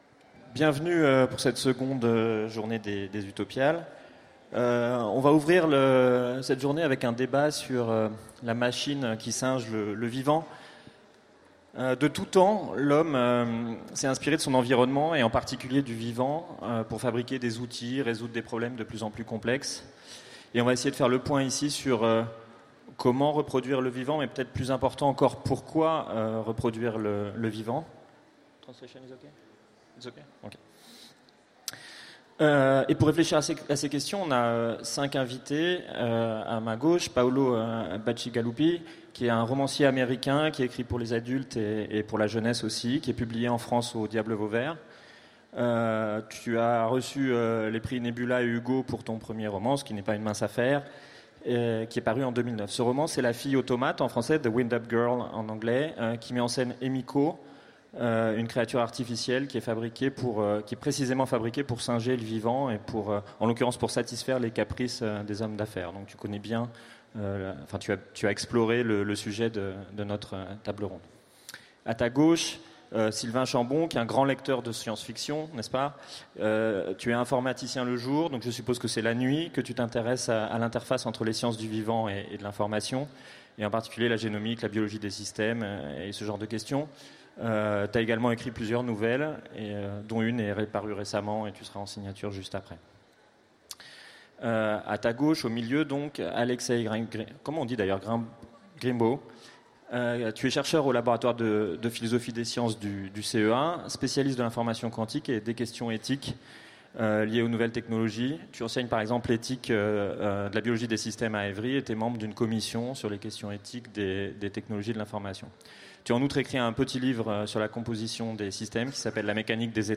Utopiales 2016 : Conférence Quand la machine singe le vivant
Conférence